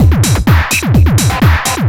DS 127-BPM A1.wav